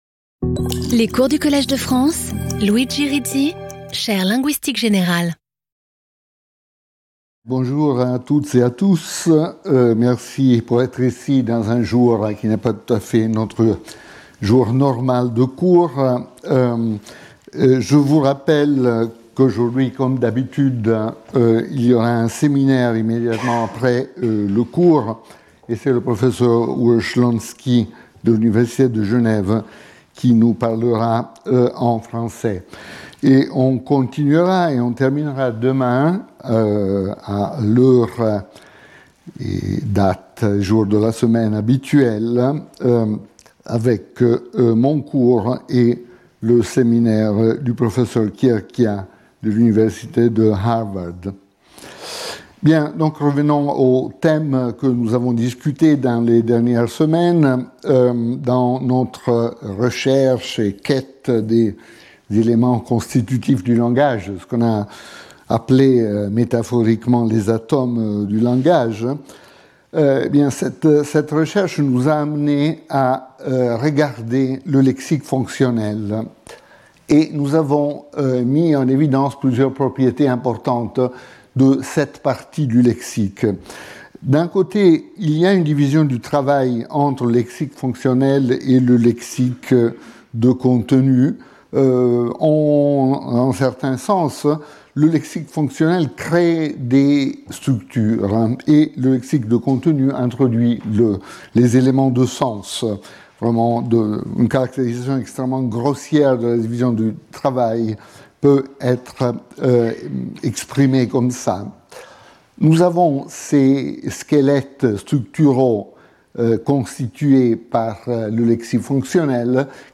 Luigi Rizzi Professeur du Collège de France
Cours